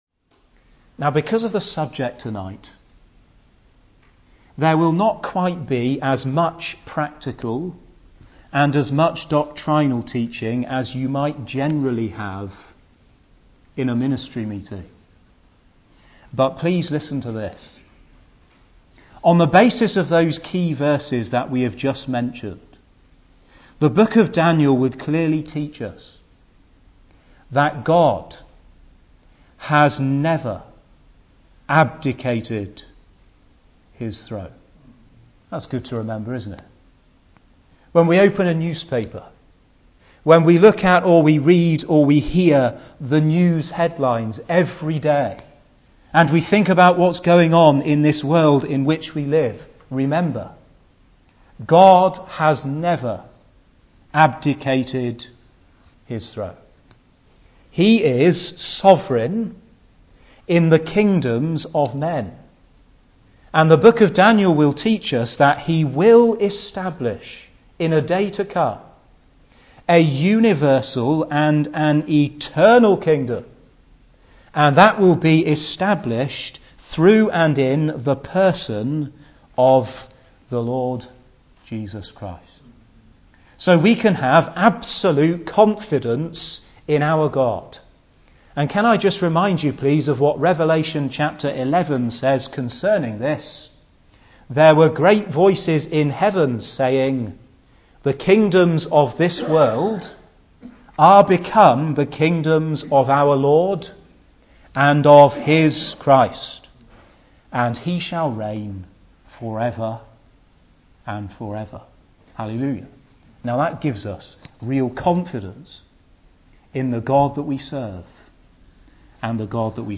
He looks at it historically, critically, Biblically, analytically, prophetically and practically. Details are given about the 3 invasions of Nebuchadnezzar, the life and times of Daniel, the major visions of the book and a good deal about the critical attacks on Daniel’s writings and how to counteract the same. For a chart of the book of Daniel to go with this message, click here (Message preached 28th Jan 2016)